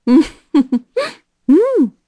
Seria-Vox_Happy4_kr.wav